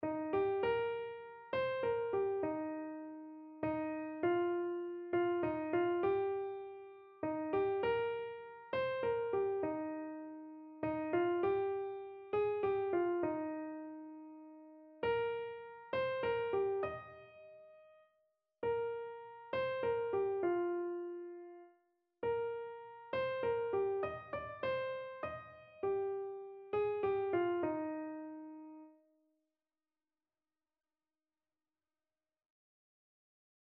Christian
Free Sheet music for Keyboard (Melody and Chords)
3/4 (View more 3/4 Music)
Classical (View more Classical Keyboard Music)